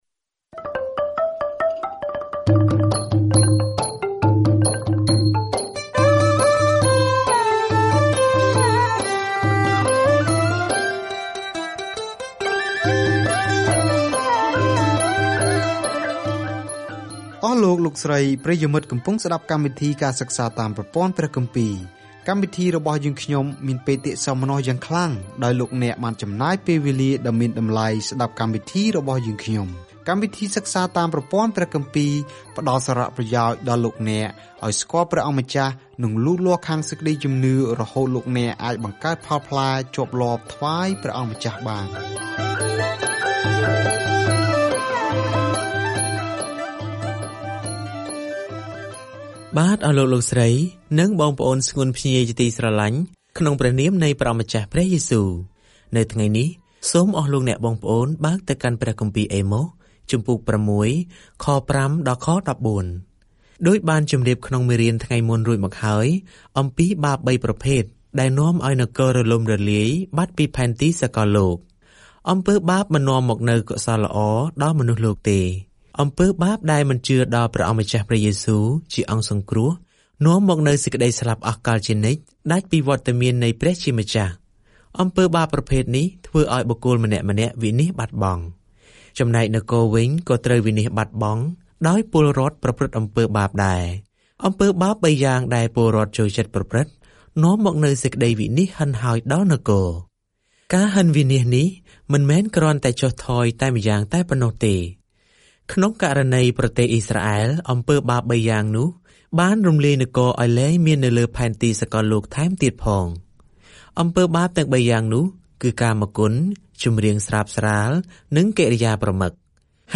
ការធ្វើដំណើរជារៀងរាល់ថ្ងៃតាមរយៈអេម៉ុស នៅពេលអ្នកស្តាប់ការសិក្សាជាសំឡេង ហើយអានខគម្ពីរដែលជ្រើសរើសពីព្រះបន្ទូលរបស់ព្រះ។